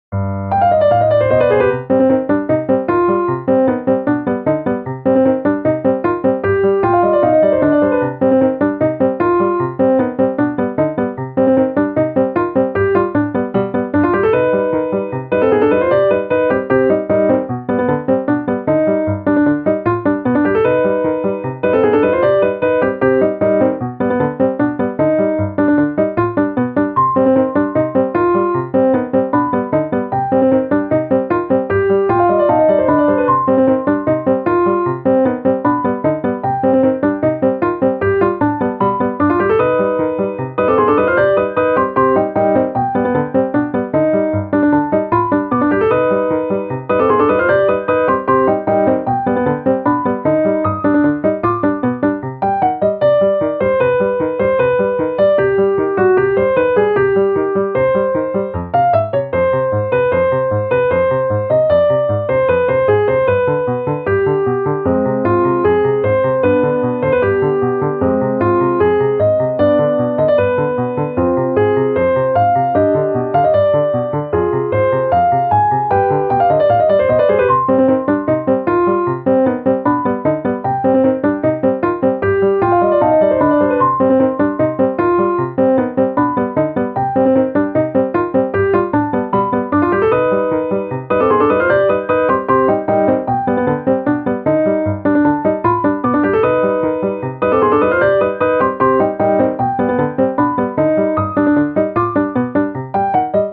ogg(L) - 疾走 せかせか 楽しい